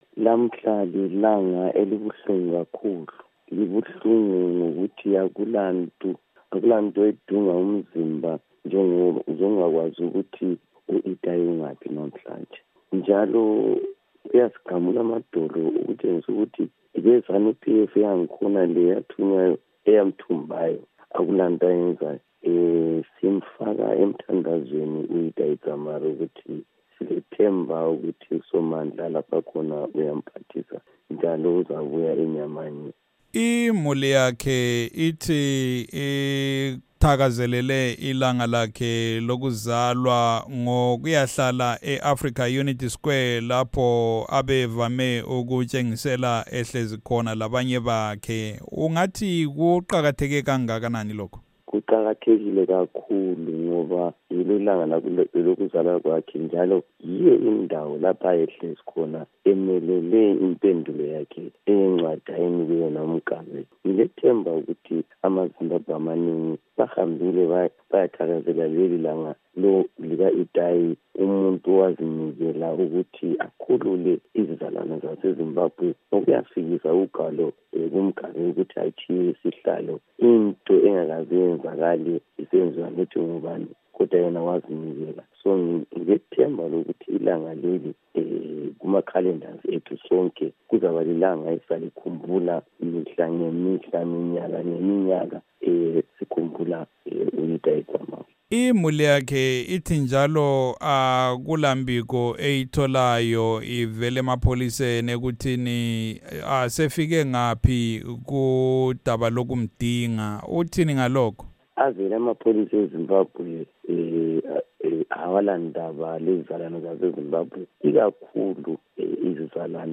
Ingxoxo loNkosazana Thabitha Khumalo